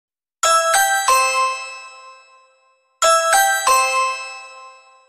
someone is at your door Sound Effects